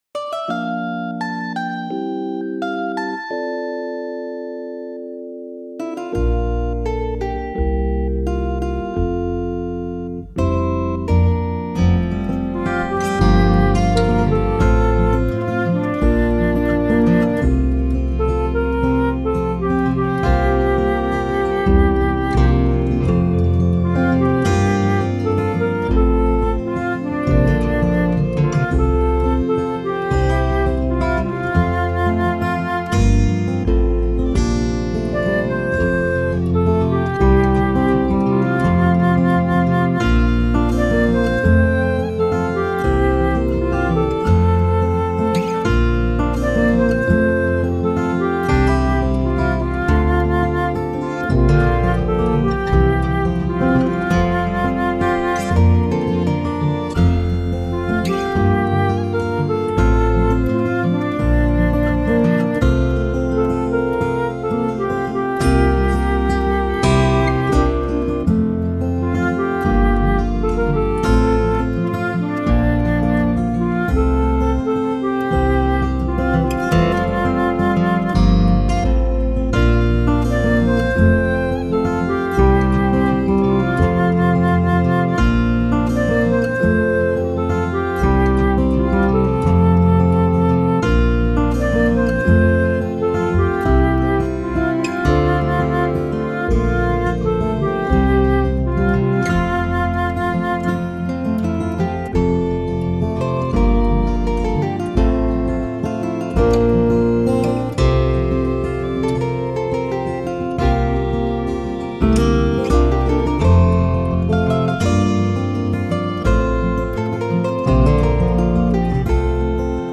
I made a singalong backing for fun. Mine is in F.